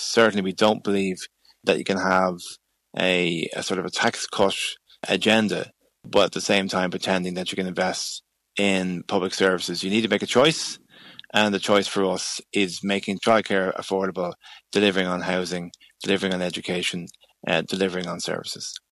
However Labour’s Aodhán Ó Riordáin doesn’t believe tax cuts are necessary or the way forward: